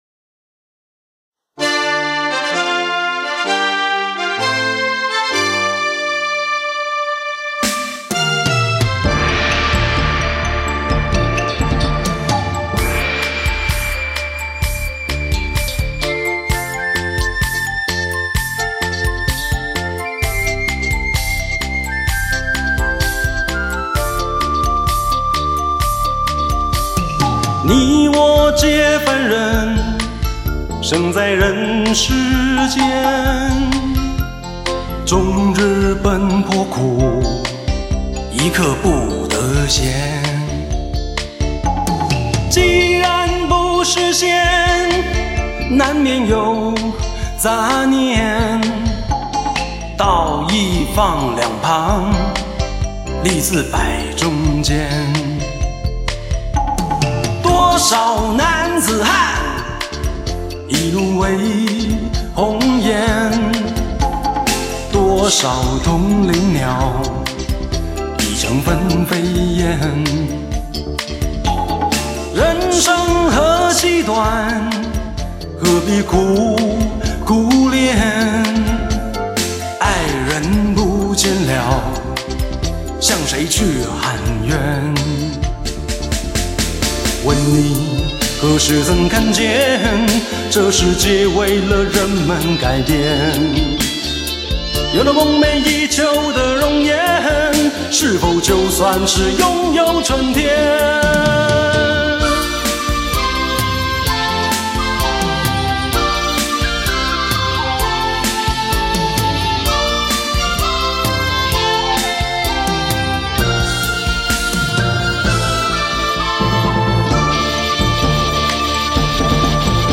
傳奇真空管錄音，美國DTS-ES6.1頂級編碼器，還原現場震撼體驗